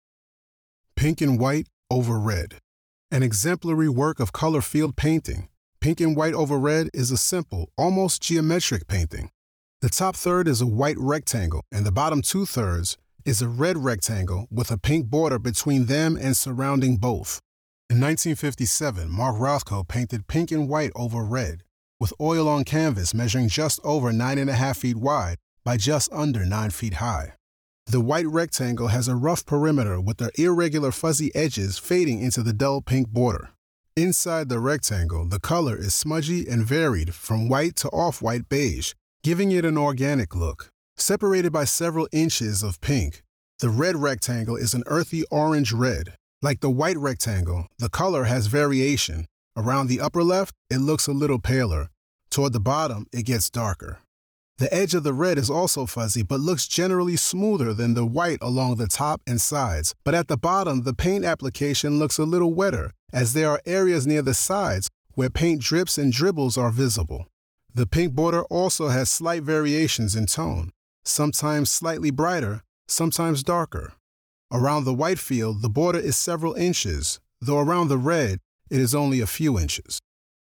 Audio Description (01:21)